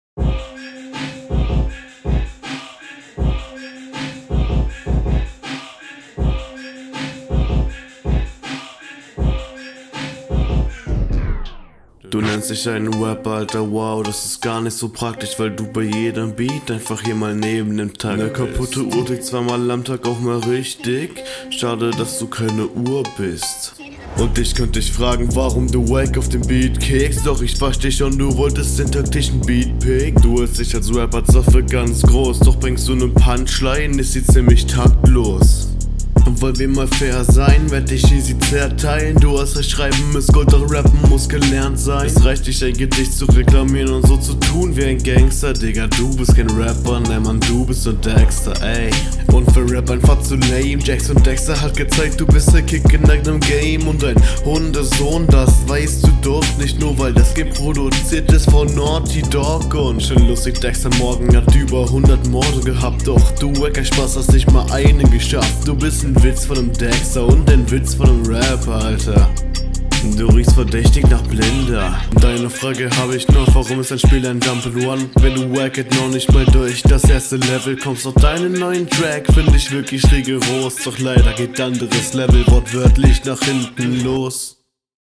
Stimme finde ich etwas Drucklos, Flowlich ist das okay, Text auch oksy für die train …
geiler Beat, passt viel besser zu dir und klingst auch viel besser darauf. Hier passt …